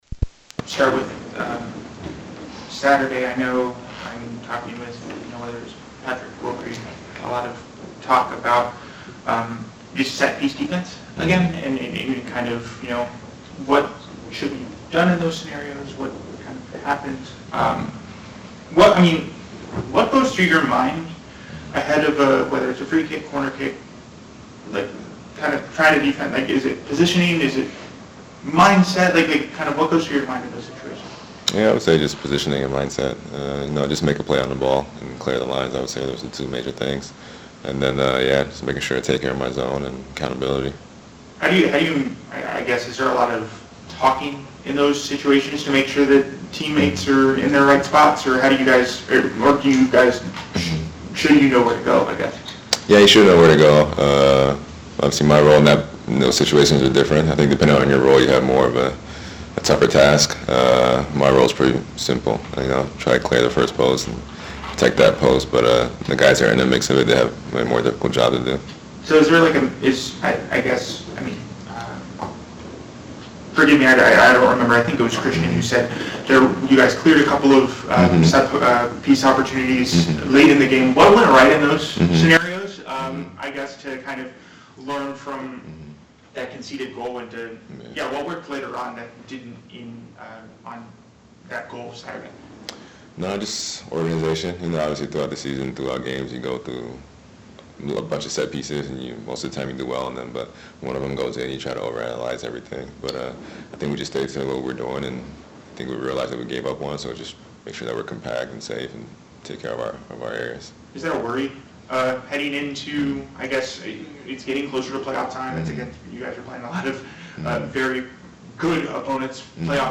Crew visits Revolution tonight in a match for Eastern Conference Playoffs positioning; Darlington Nagbe gives preview